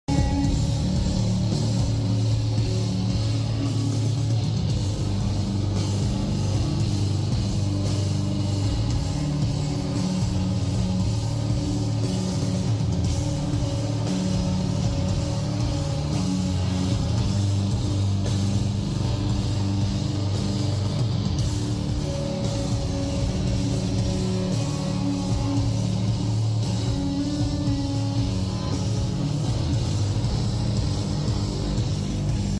live at the House of Blues, Anaheim, CA